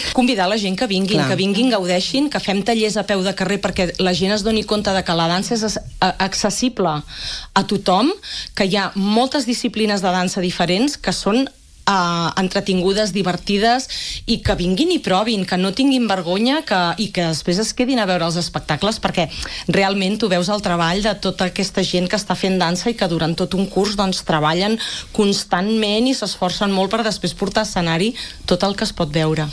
han passat pels micròfons del matinal de RCT